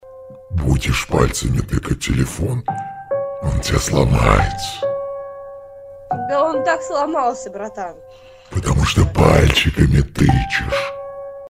Звуки дьявола